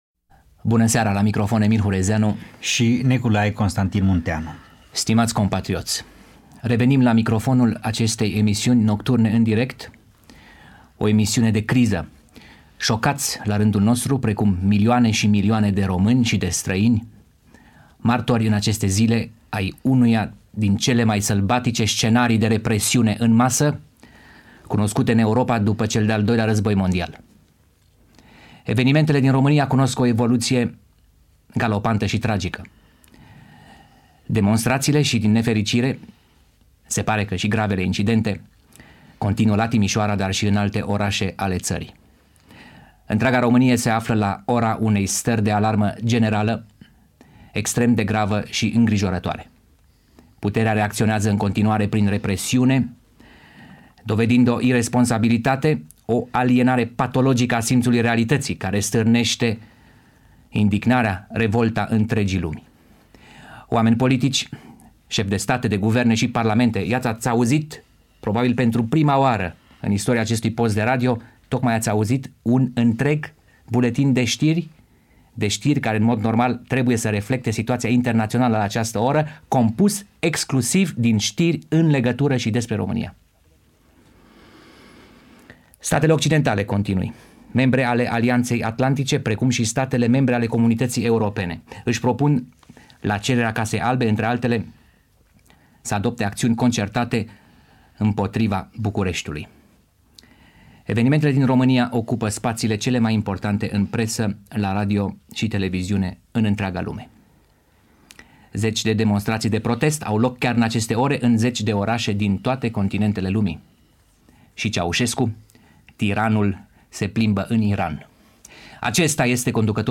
În această emisiune moderată de Emil Hurezeanu sunt dezbătute reacțiile internaționale referitoare la evenimentele care avusese loc la Timișoara, București și alte orașe ale țării.